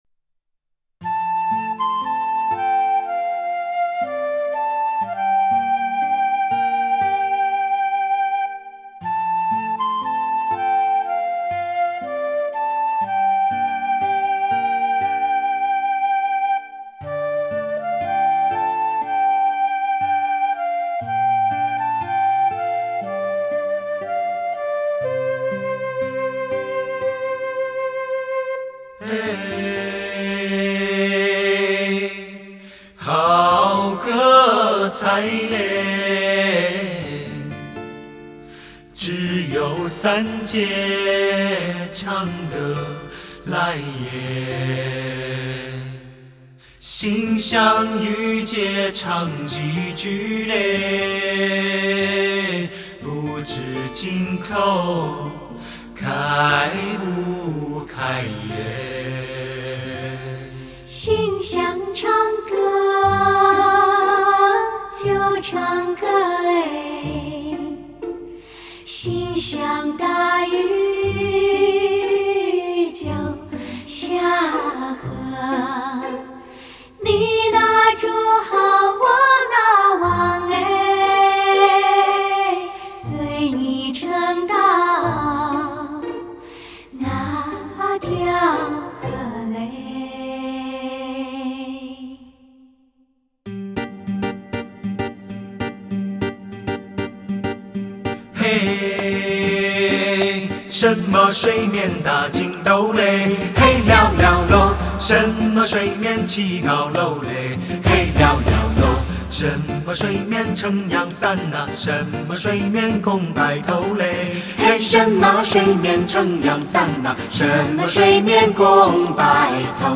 让人听起来非常恬静和舒适。
男女声齐唱与对唱